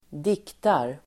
Uttal: [²d'ik:tar]